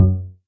bassattack.ogg